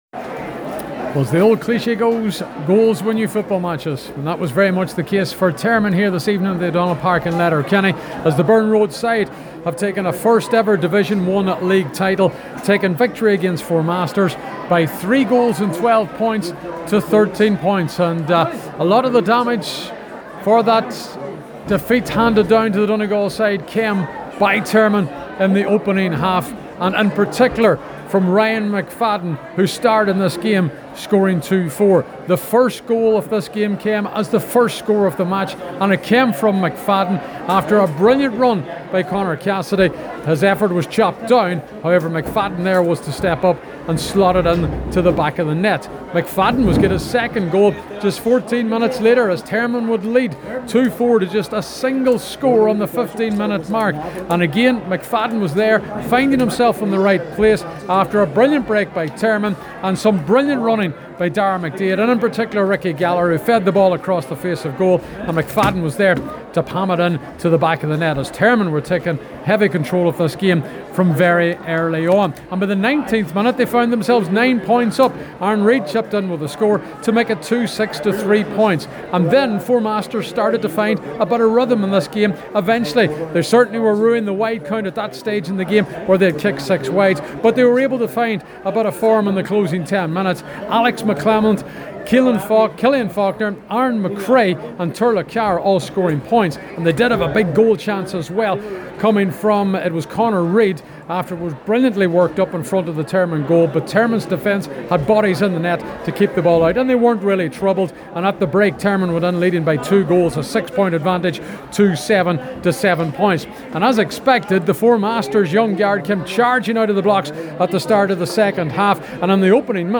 reports from the O’Donnell Park in Letterkenny: